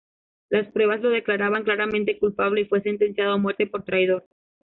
Read more Adj Noun Frequency B1 Hyphenated as cul‧pa‧ble Pronounced as (IPA) /kulˈpable/ Etymology From Latin culpābilis.